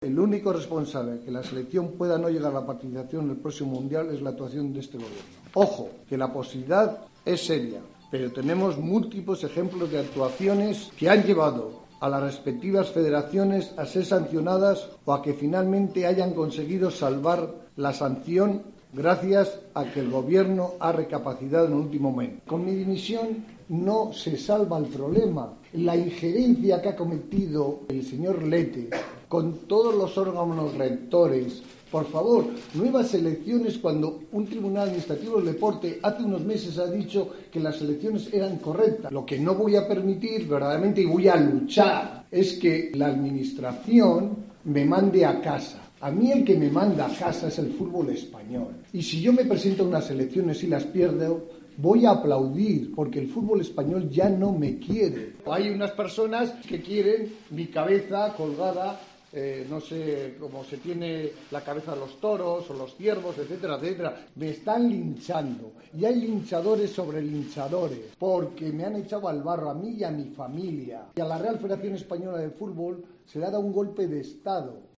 Escucha algunas de las frases más destacadas de la rueda de prensa de Ángel María Villar este lunes, ante los medios de comunicación: "La situación es seria, y si otras Federaciones han salvado la sanción de la FIFA es porque los Gobiernos han recapacitado.